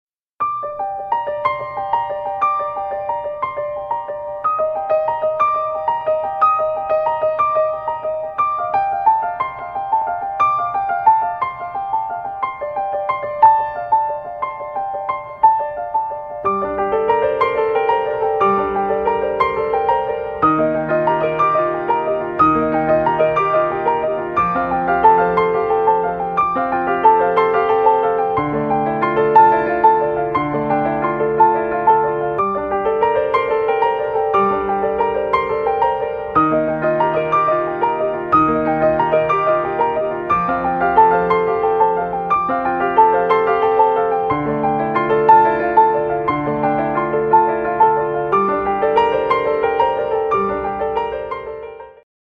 • Качество: 128, Stereo
фортепиано